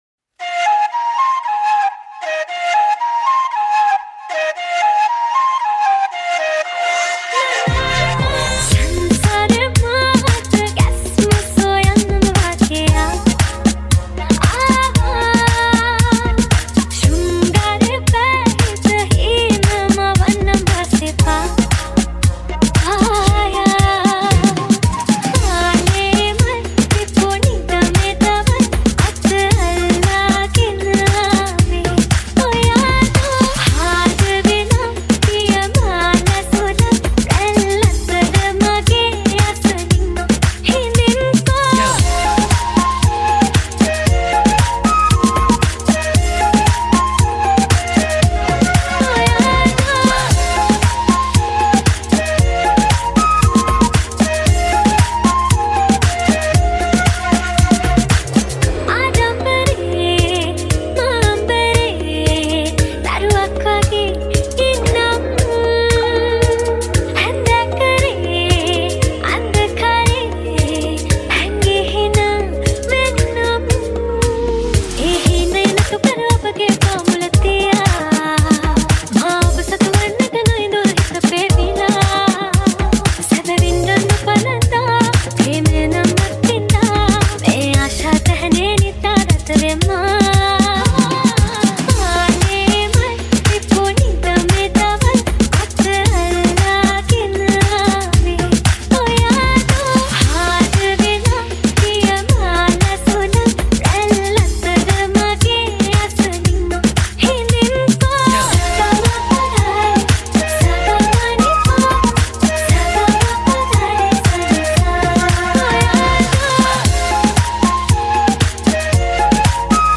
Reggaeton Remix